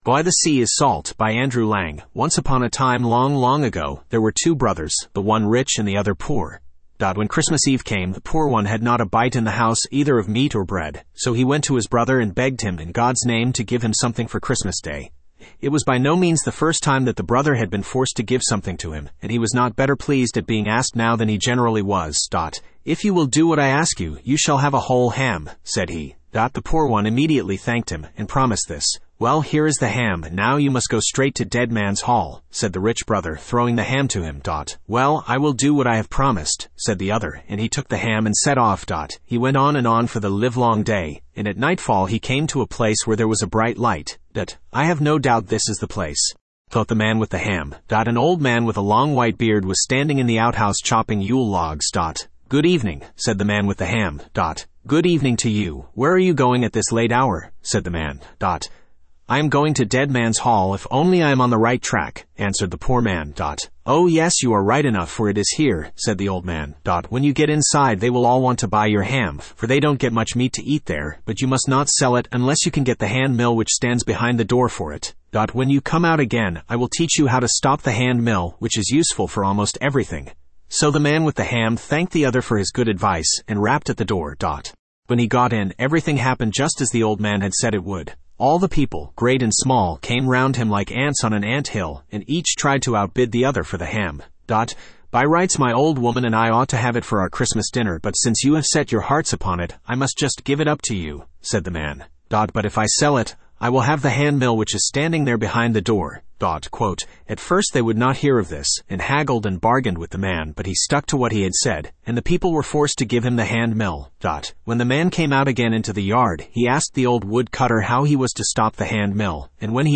Studio (Male)